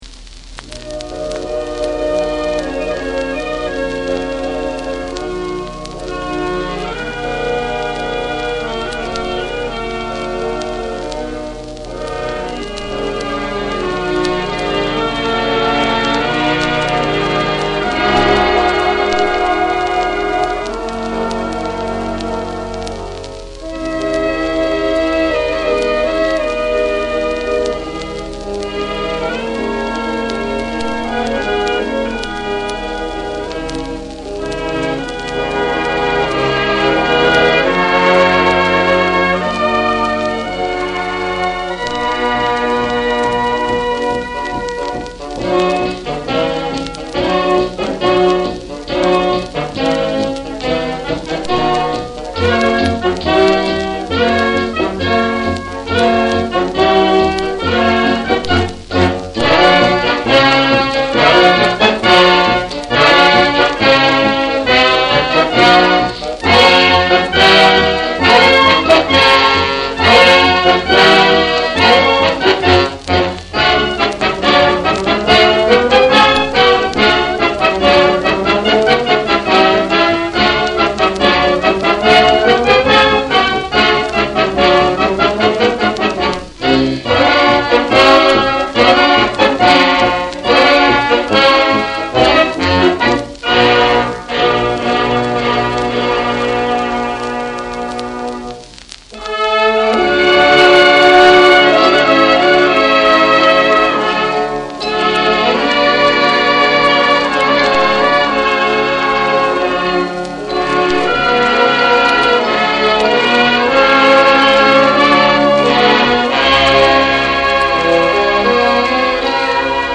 military band